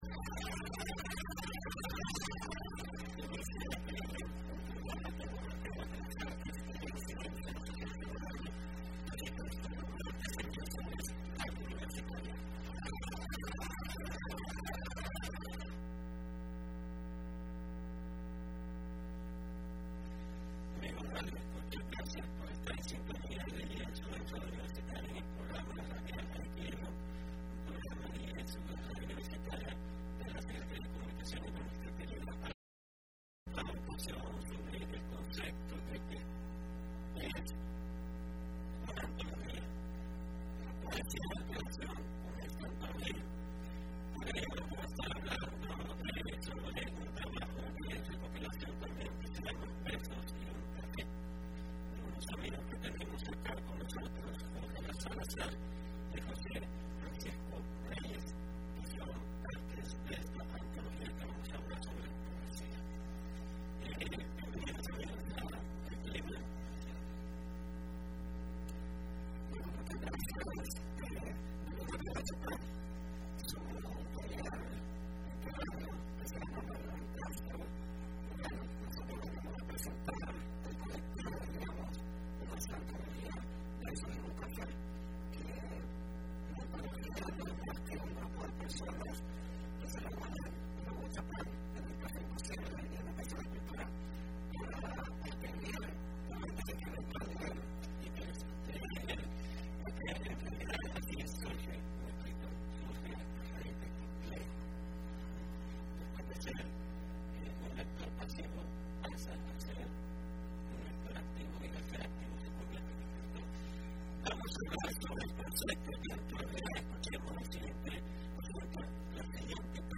Entrevista programa Aequilibrium (17 marzo 2015) : Antología poética Versos y un café